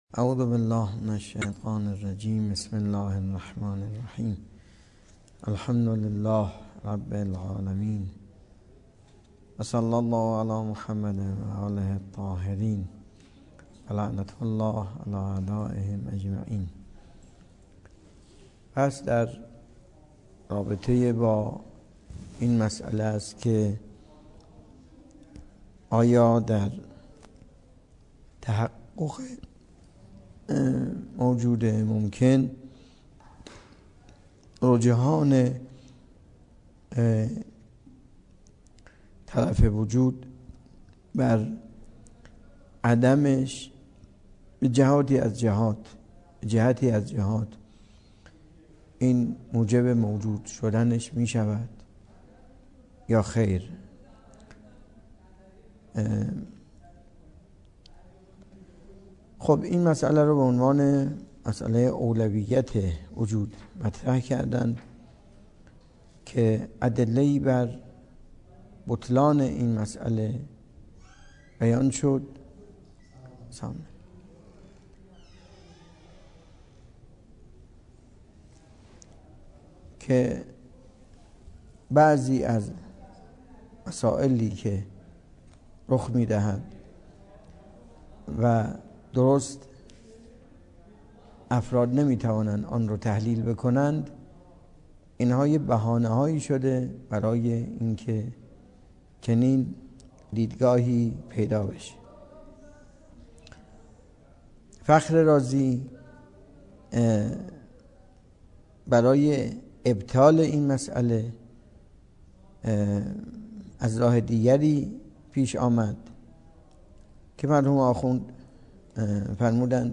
درس فلسفه اسفار اربعه
سخنرانی